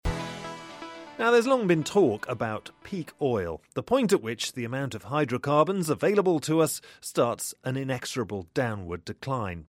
【英音模仿秀】重返节俭 听力文件下载—在线英语听力室